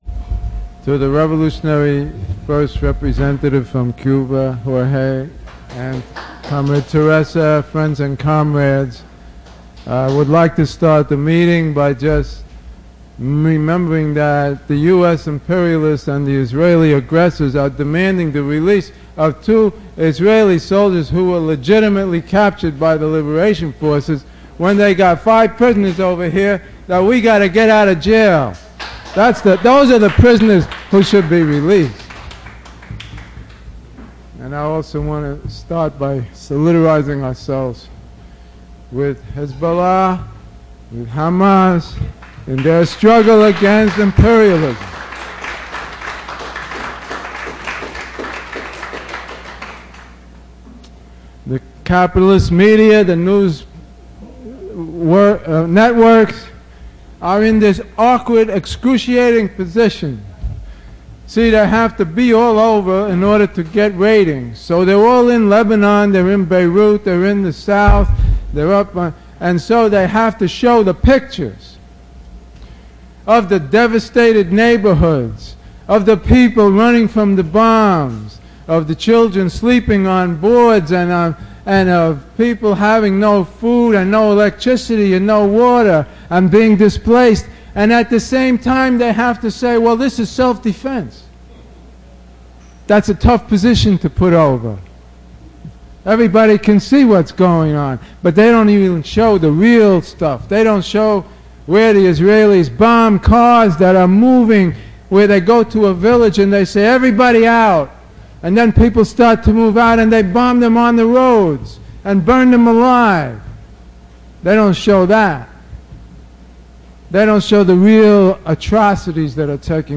speaking at a Workers World Forum in New York on July 21.